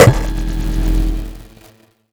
sci-fi_spark_electric_device_hum_02.wav